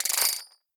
pull_chain_end.ogg